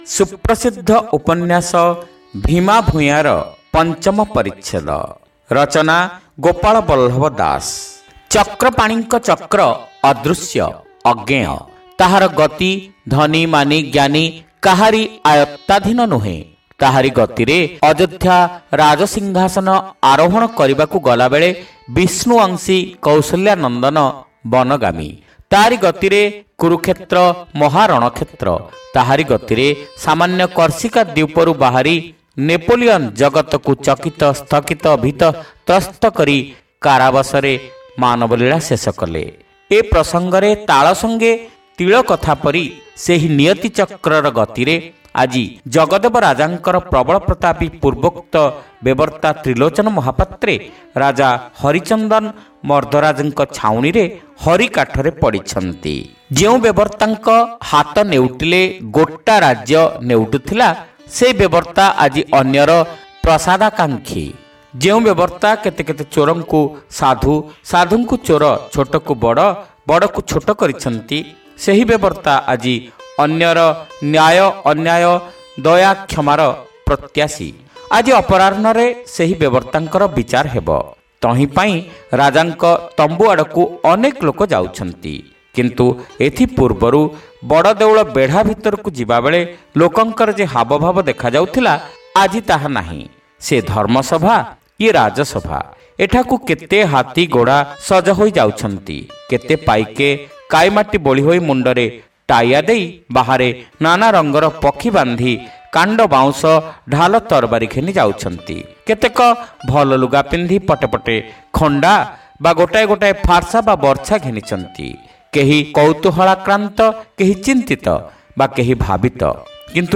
ଶ୍ରାବ୍ୟ ଉପନ୍ୟାସ : ଭୀମା ଭୂୟାଁ (ପଞ୍ଚମ ଭାଗ)